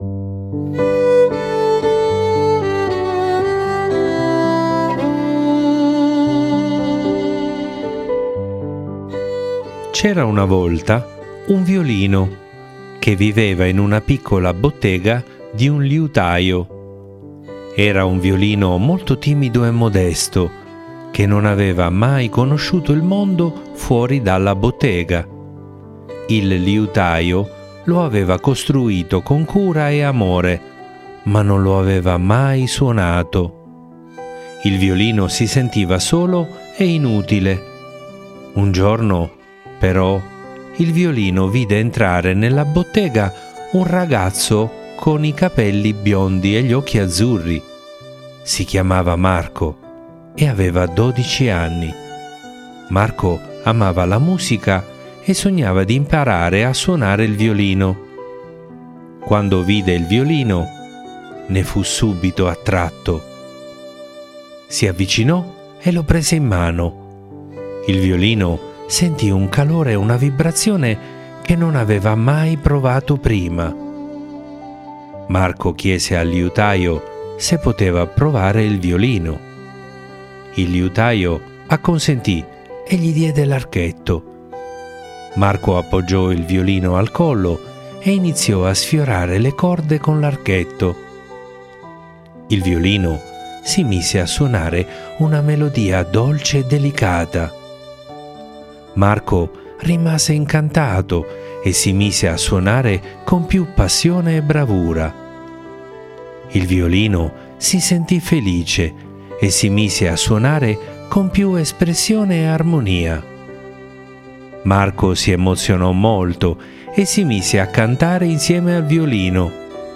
Le favole della buonanotte